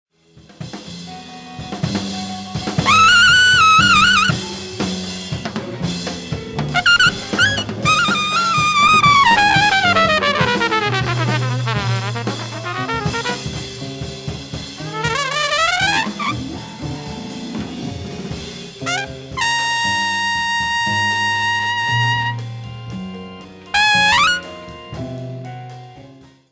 LIVE AT FILLMORE EAST, NEW YORK 06/20/1970
サウンドボード録音